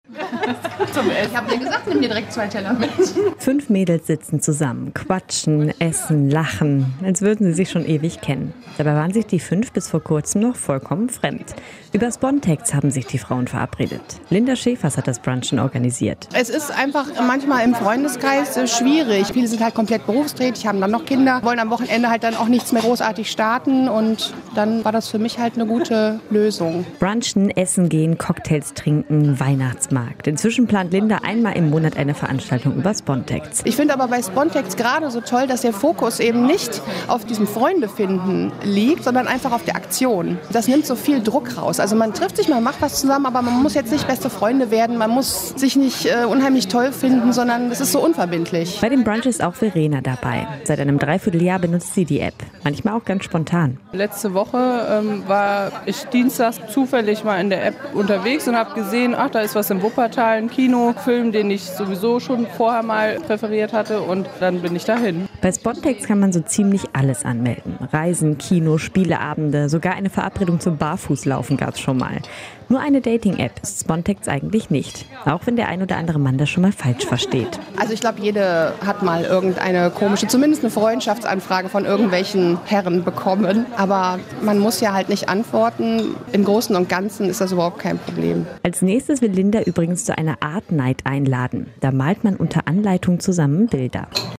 Wir haben mit Usern aus dem RSG-Land gesprochen.